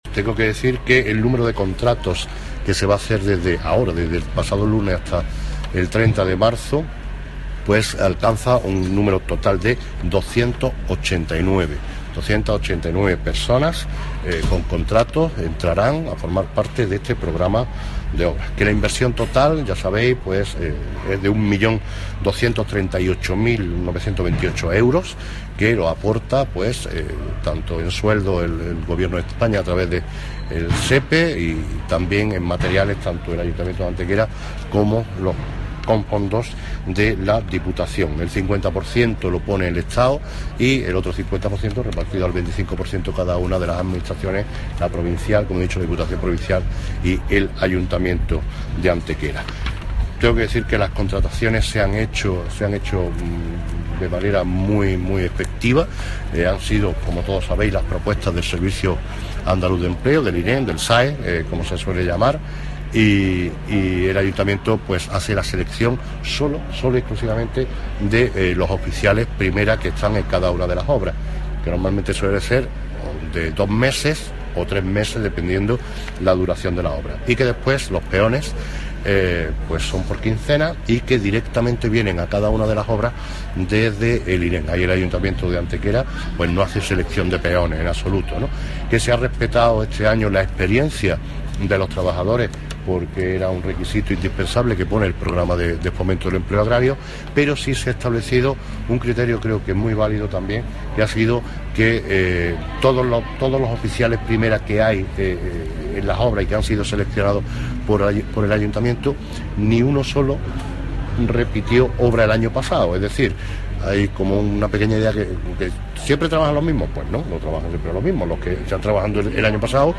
El alcalde de Antequera, Manolo Barón, y el teniente de alcalde delegado de Obras y Mantenimiento, José Ramón Carmona, han confirmado hoy en rueda de prensa que ya han comenzado los trabajos relativos a siete de los proyectos incluidos en el PROFEA 2016.
Cortes de voz